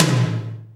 HR16B  TOM 2.wav